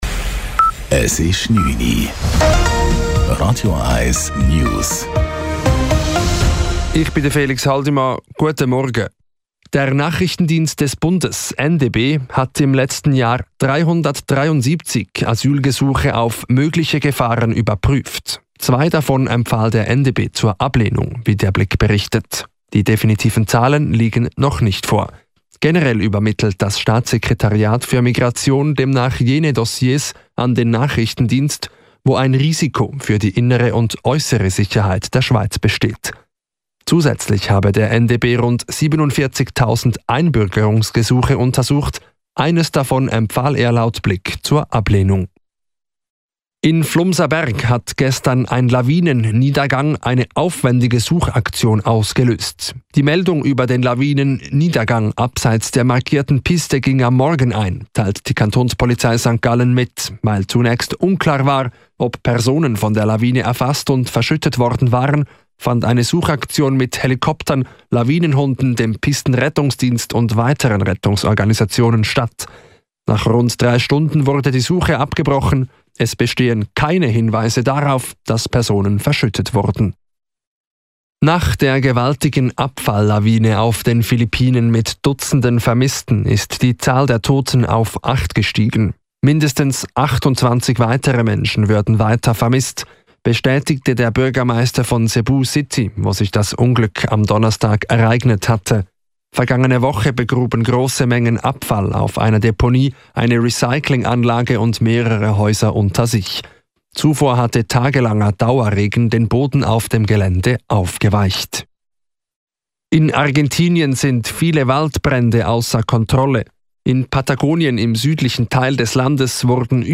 Nachrichten & Politik